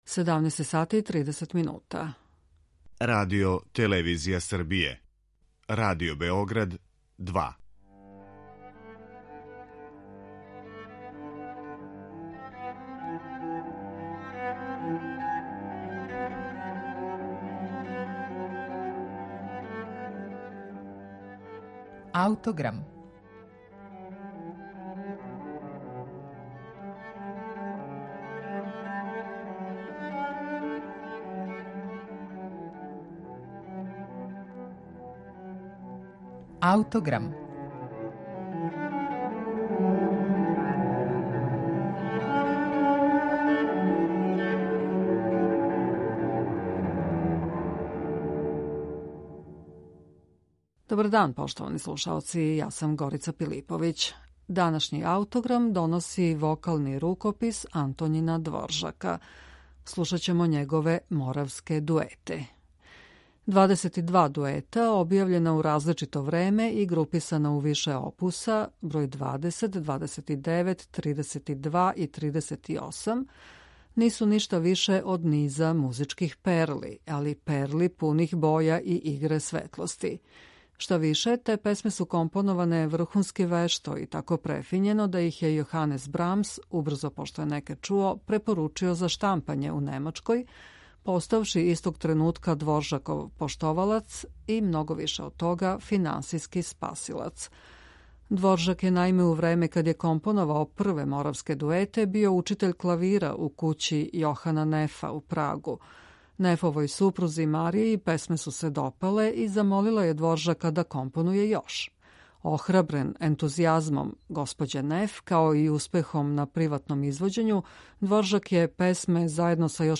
Велики чешки композитор Антоњин Дворжак, као представник националног правца у музици, обрадио је 20-ак моравских народних мелодија за два гласа и клавир.